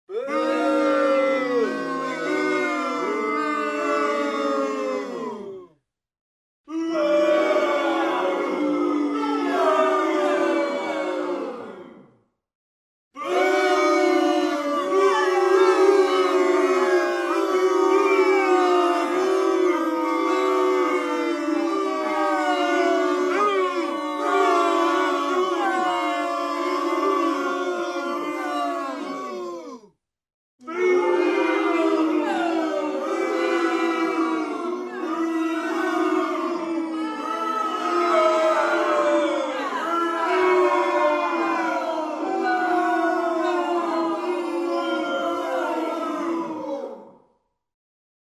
Звуки недовольной толпы
Звук недовольной толпы — фууу